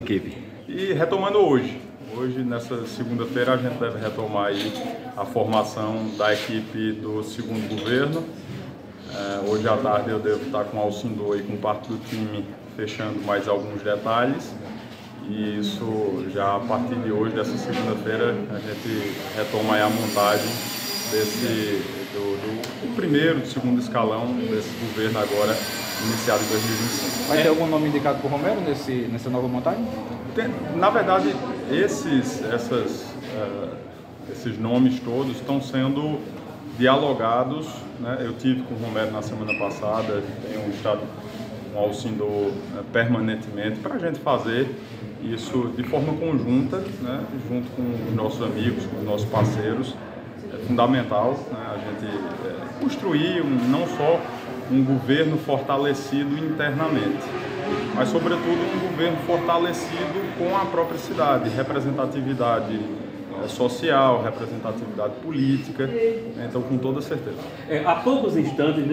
Em entrevista ao programa Correio Debate, da Rádio Correio 98 FM, o gestor revelou que tem conversado com o vice-prefeito, Alcindor Villarim, com o deputado federal Romero Rodrigues (PSC) e com membros da equipe para fechar os últimos detalhes e que, por meio de unidade, construir a nova base de secretários na Rainha da Borborema.